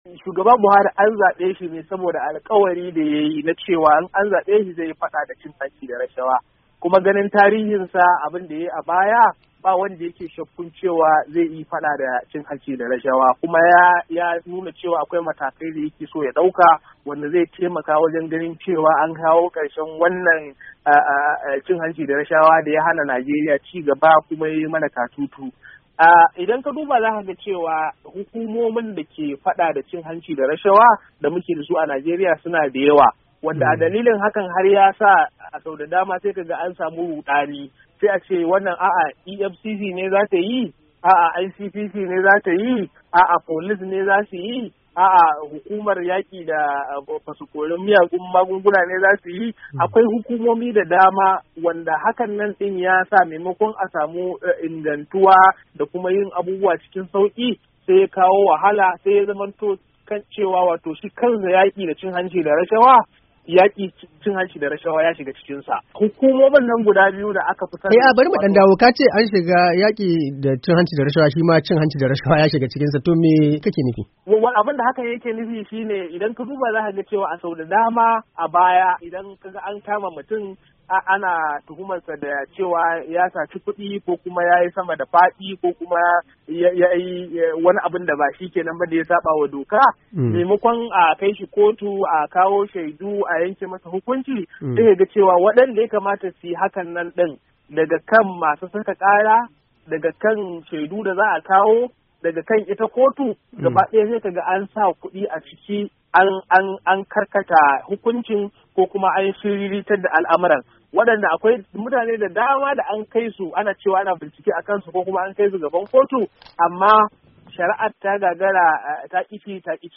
A wata hirar da ya yi ta waya